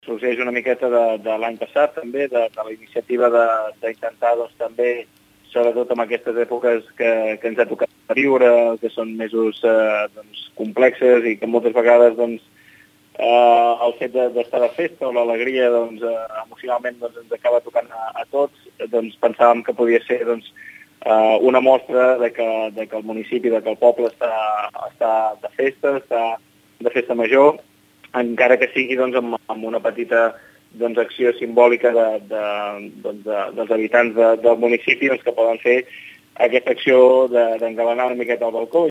El regidor de festes, Pau Megias, explica d’on sorgeix la iniciativa.